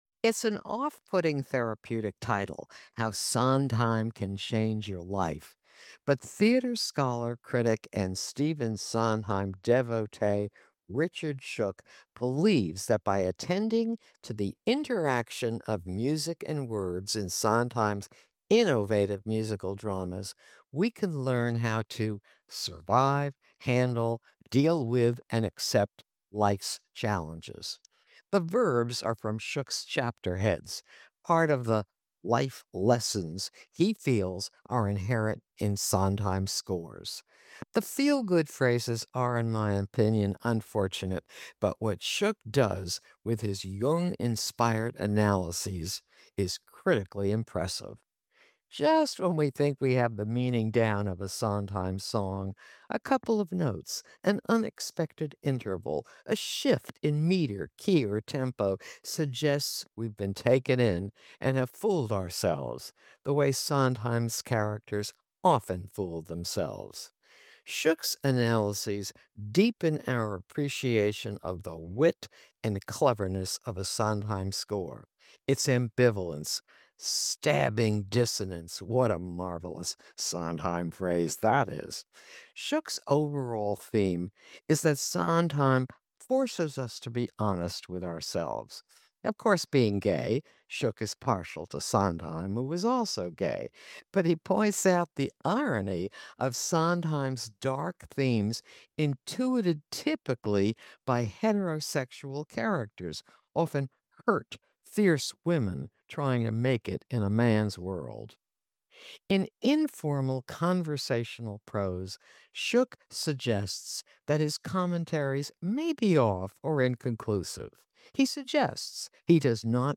Book Review: How Sondheim Can Change Your Life 4:51